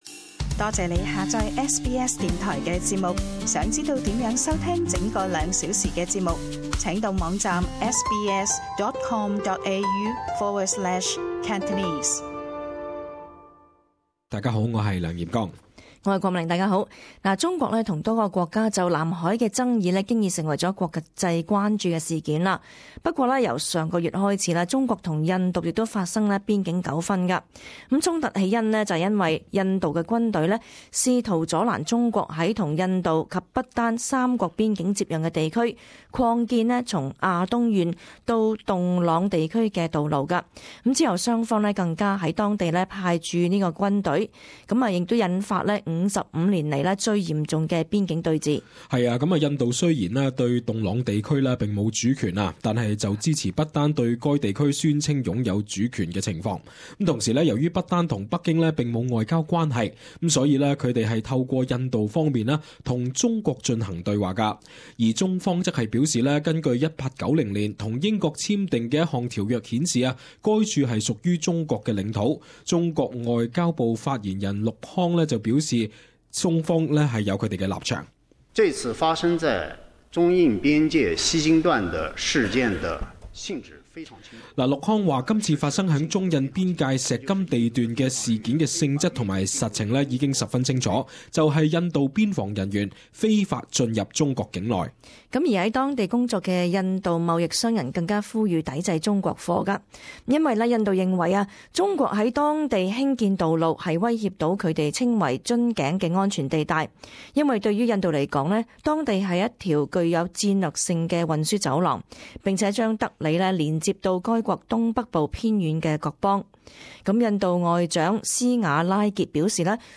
【时事报导】 中印发生55年来最严重边境纠纷